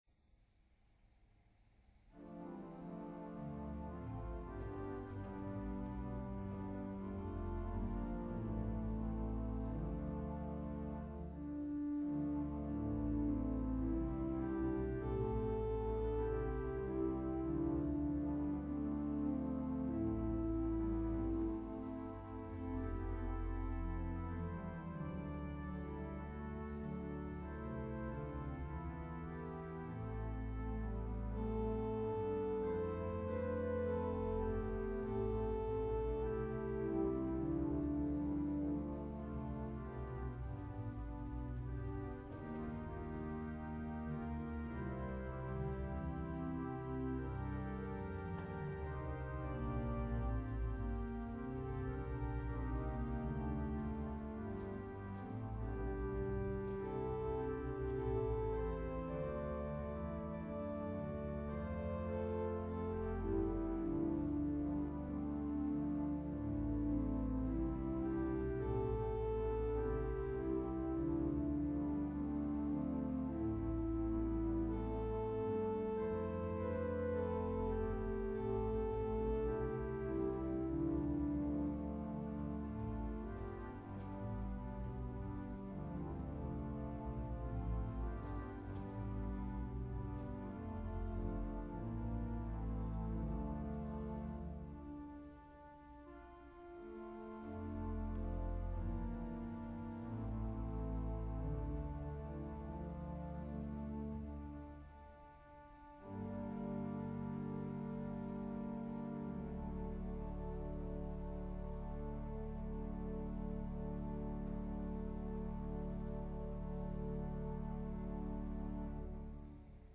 • Music Type: Organ